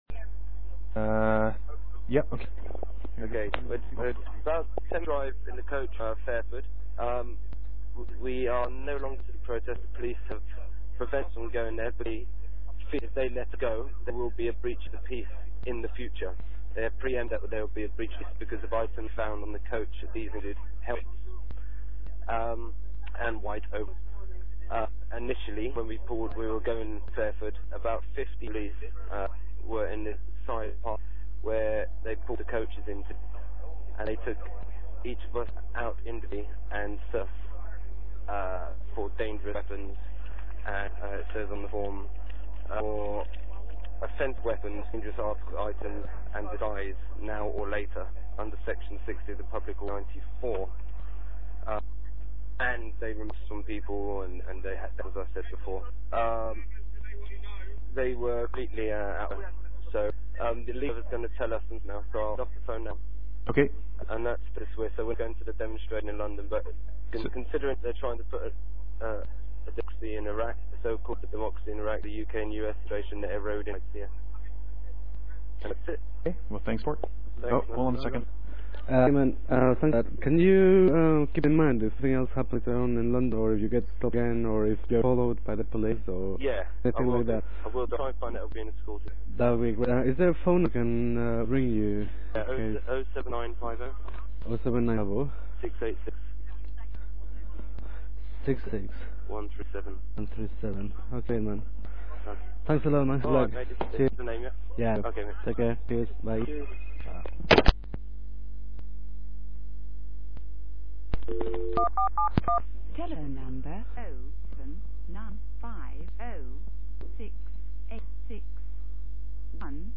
audio interviews from fairford demonstators
2 audio interviews from Fairford demonstrators conducted via cellphone. The protest was prevented from reaching the B-52 bomber base by police using Section 60 powers of preventative search and the demonstrators' coaches are now being escorted back to London by police who have closed motorway access around the coaches.